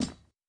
Impact on Wood.wav